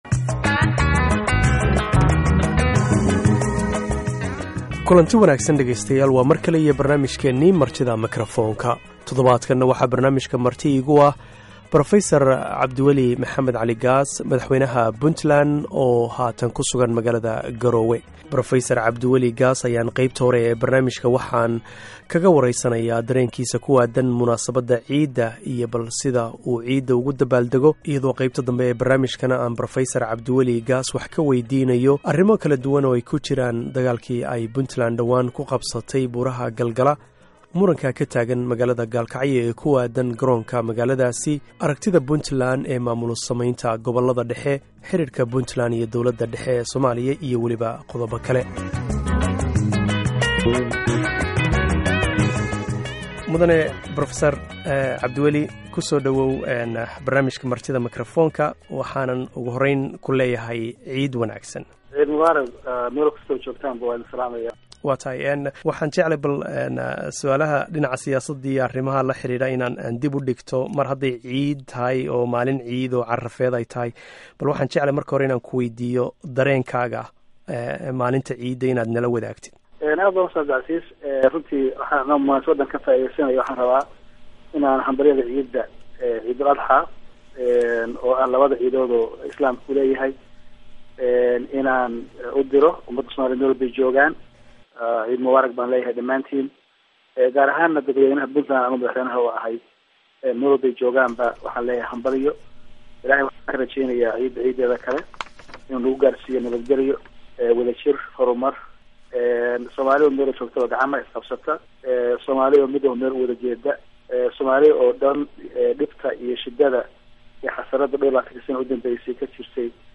Dr. Cabdiweli Maxamed Cali Gaas, madaxweynaha Puntland ayaa maanta marti ku ah barnaamijka Martida Makrafoonka. Dr. Gaas ayaa ka hadlaya dareenkiisa ku aadan ciidda iyo arrimo kale duwan.